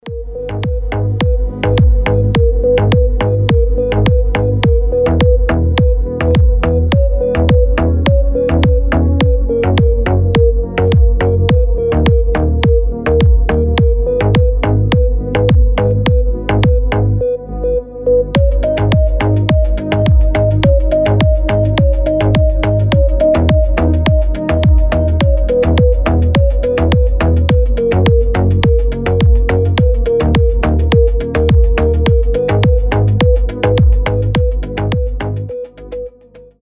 Рингтоны ремиксы , Танцевальные рингтоны
Deep house